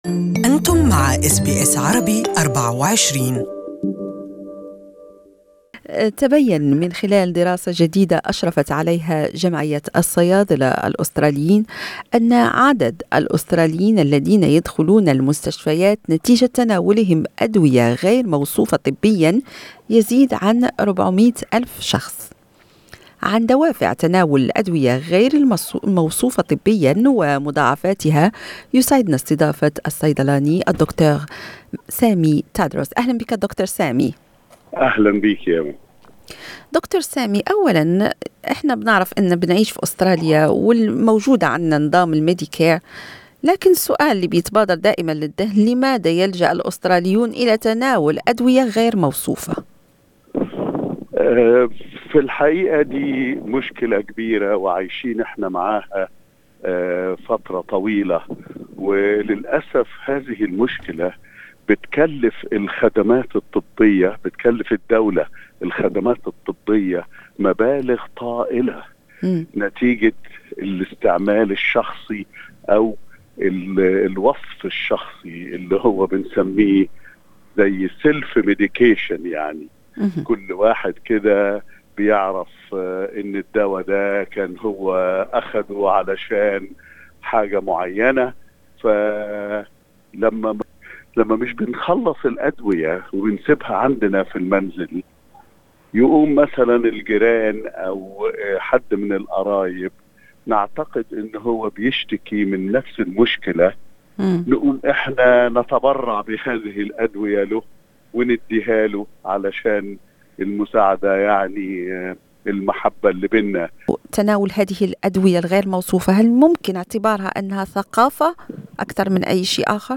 أس بي أس عربي